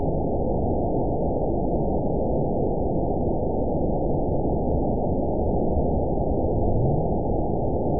event 920364 date 03/19/24 time 00:11:16 GMT (1 month, 1 week ago) score 9.32 location TSS-AB07 detected by nrw target species NRW annotations +NRW Spectrogram: Frequency (kHz) vs. Time (s) audio not available .wav